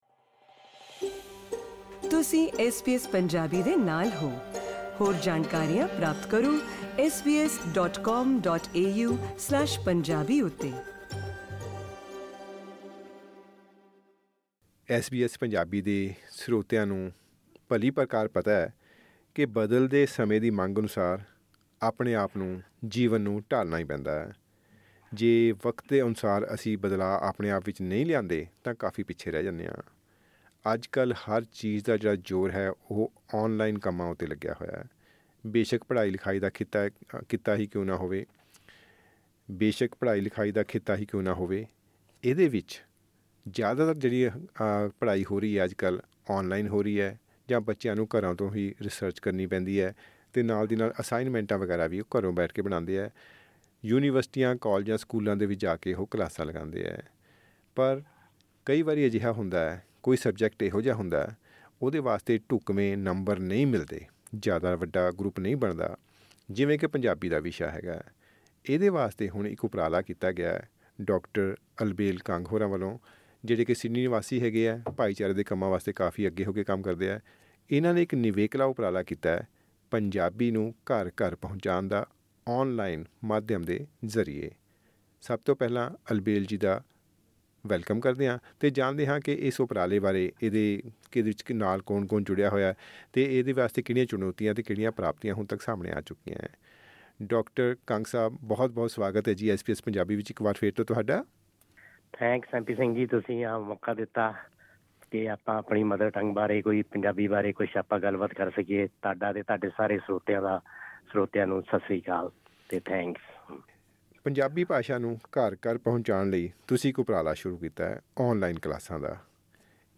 ਗੱਲਬਾਤ